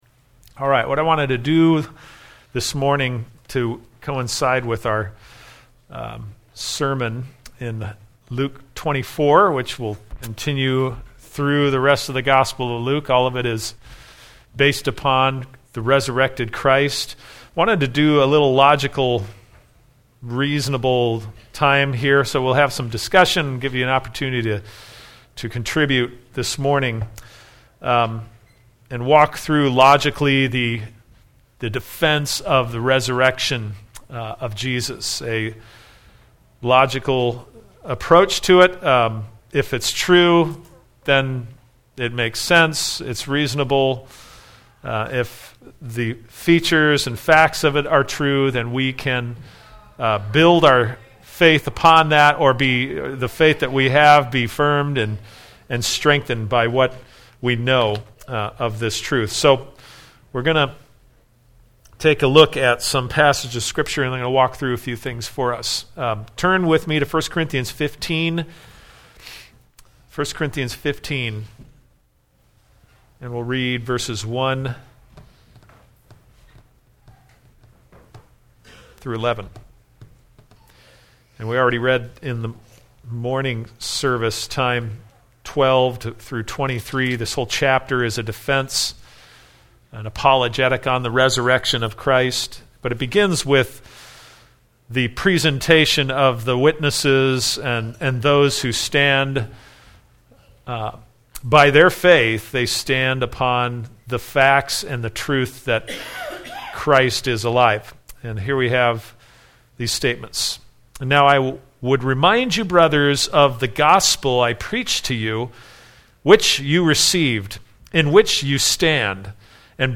A message from sunday school